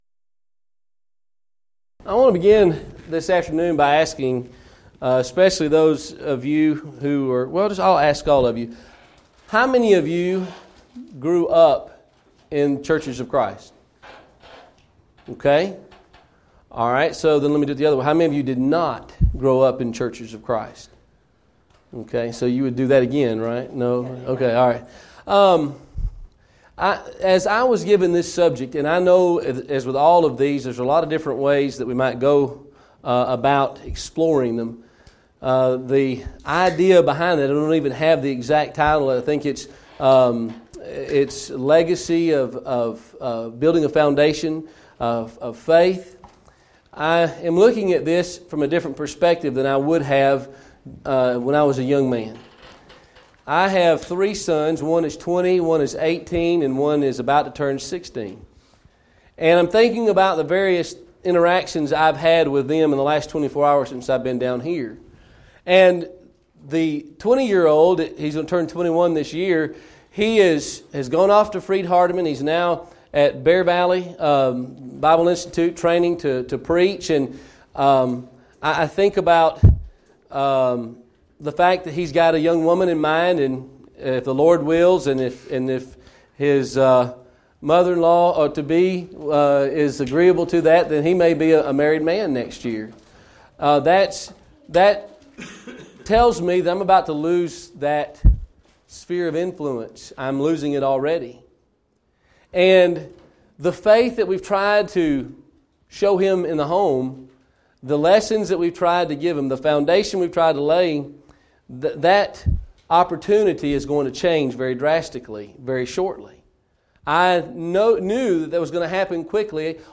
Event: 2014 Focal Point
lecture